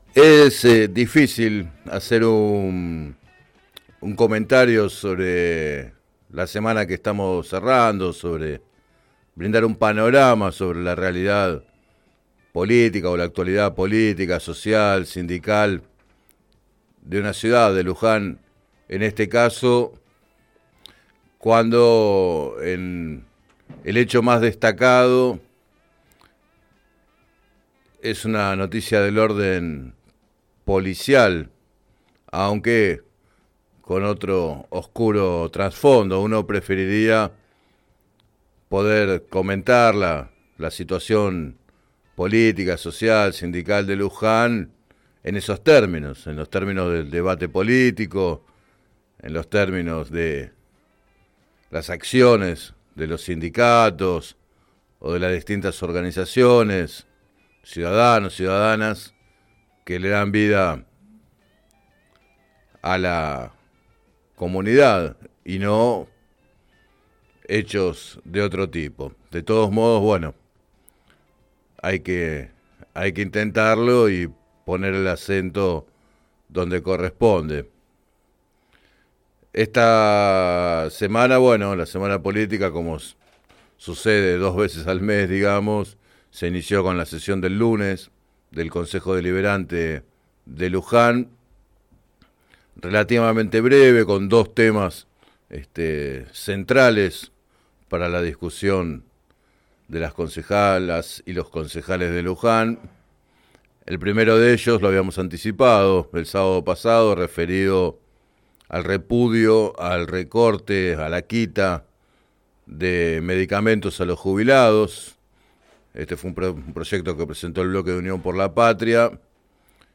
En la apertura del programa Planeta Terri